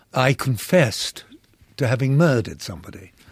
Listen to this contrast in actor Derek Jacobi’s voice: